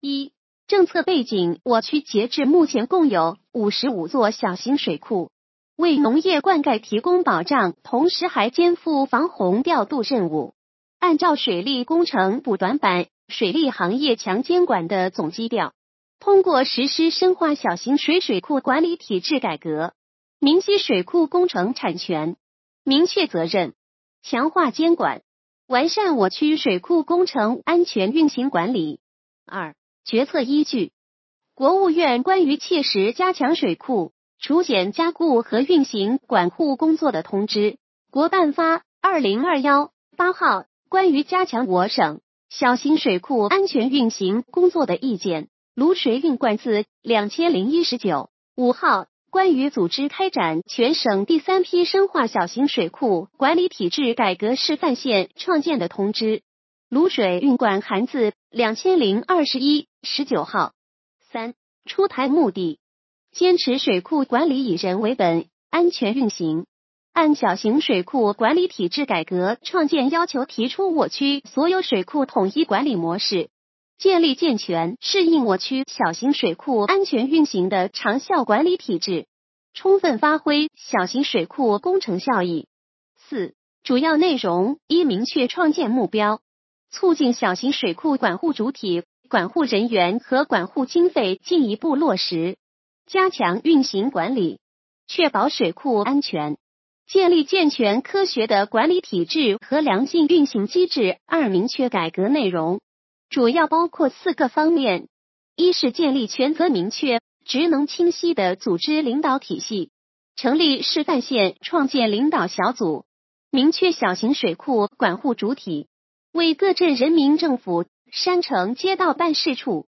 语音解读：山亭区人民政府办公室关于印发山亭区深化小型水库管理体制改革示范县创建实施方案的通知